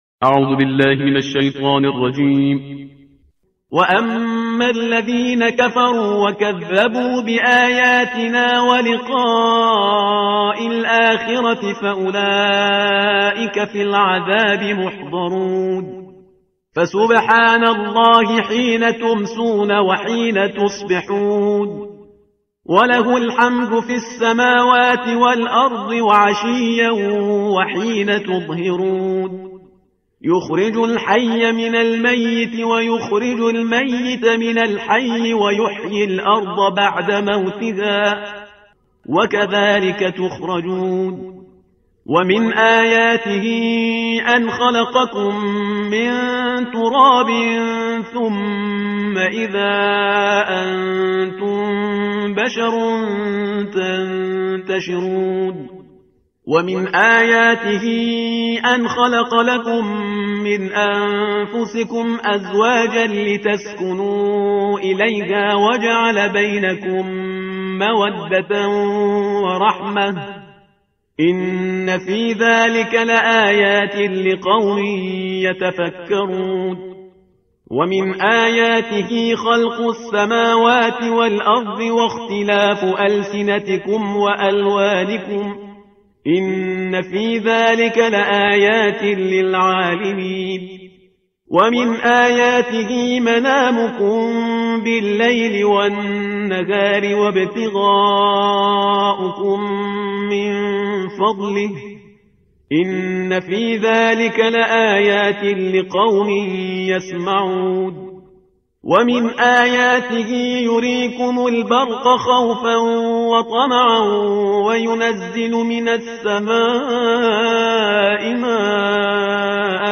ترتیل صفحه 406 قرآن